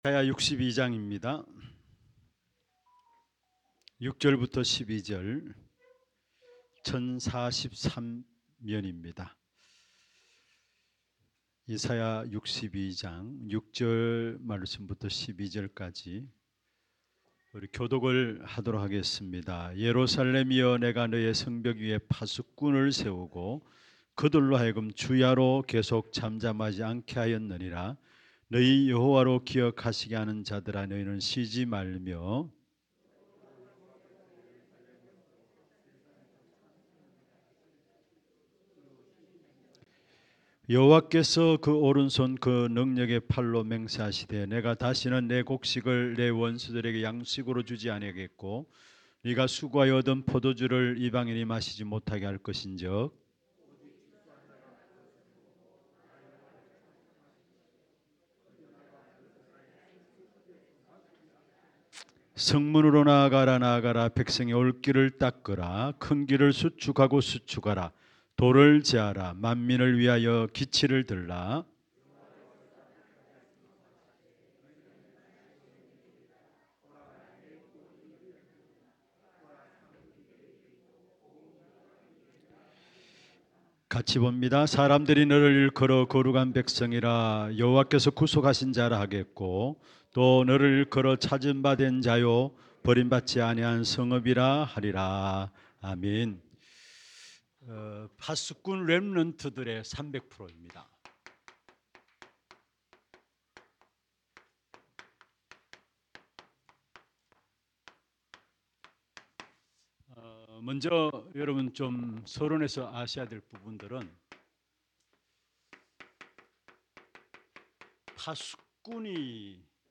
권찰회